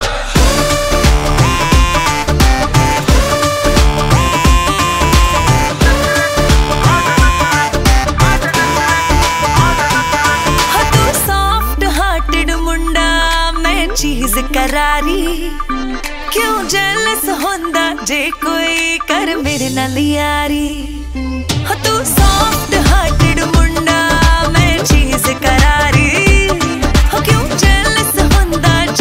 Tono bollywood